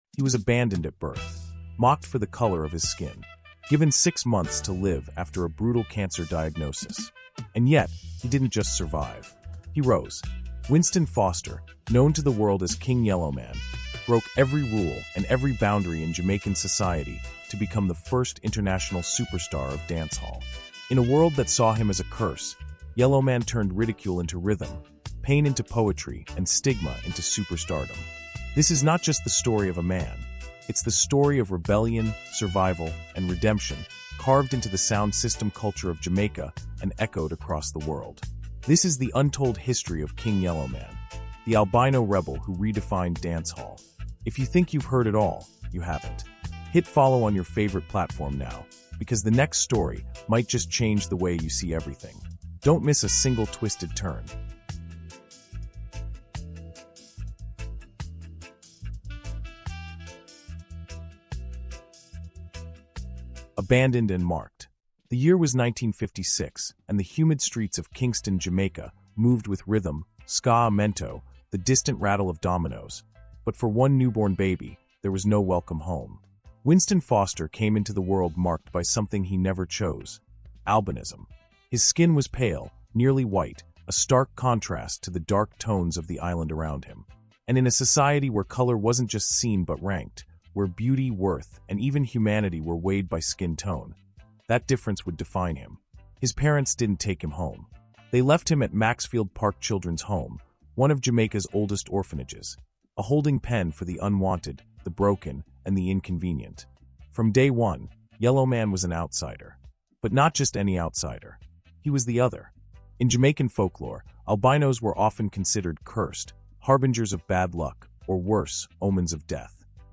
CARIBBEAN HISTORY: KING YELLOWMAN — THE ALBINO REBEL WHO REDEFINED DANCEHALL is a powerful documentary exploring the life of Winston Foster, better known as King Yellowman — Jamaica’s first global dancehall superstar. Born with albinism, abandoned as a child, and raised in a tough orphanage, Yellowman faced intense bullying, colonial-era stigma, and even cancer — yet emerged as a pioneering force in Caribbean music, black history, and cultural resilience.